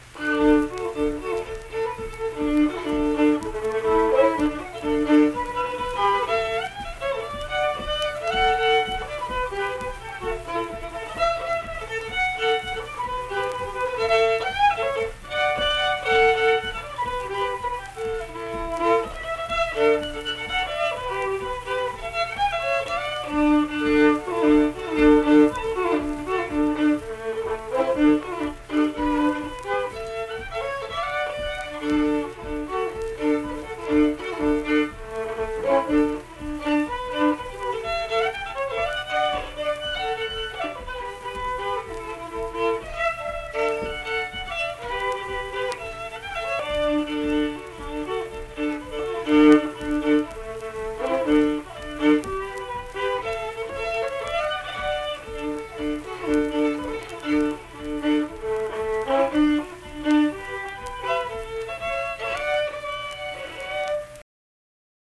Unaccompanied fiddle performance
Instrumental Music
Fiddle